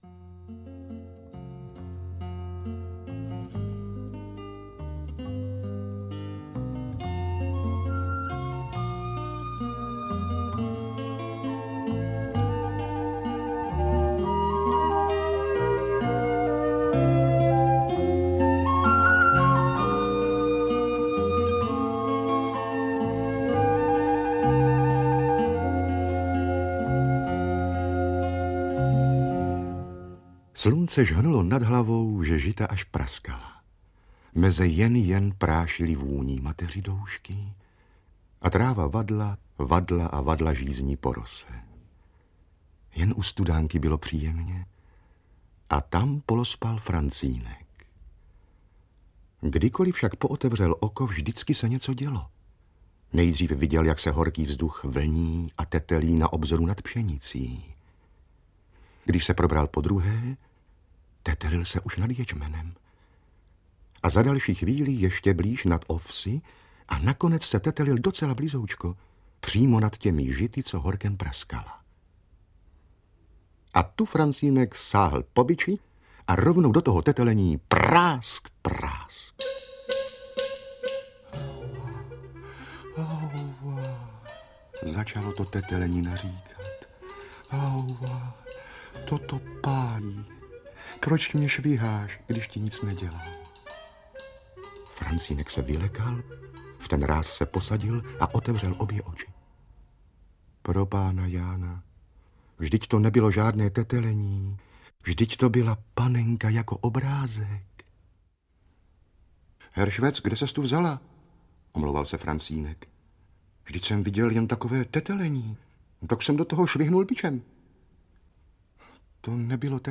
Interpret:  Eduard Cupák
Audiokniha obsahuje pohádku Františka Nepila v podání Eduarda Cupáka.